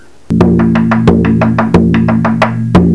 The X marks represent the Ka syllable - or a beat on the fuchi (edge of the drum).
Click on any of the staffs to hear it played, or click on the bottom to hear one repetion of section three.